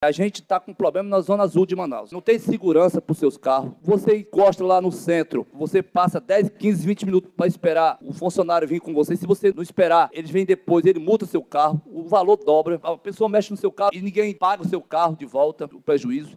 Durante a Sessão Plenária desta segunda-feira 11/12, na Câmara Municipal de Manaus, vereadores questionaram o reajuste de 62,44% na tarifa do Zona Azul.
O vereador Sassá da Construção, do PT, que é da base aliada da Prefeitura, foi o primeiro que pediu explicações, e sugeriu a instalação de uma Comissão Parlamentar de Inquérito – CPI para investigar a administração dos estacionamentos privativos. Durante o pronunciamento, o parlamentar citou vários problemas enfrentados pelos usuários do serviço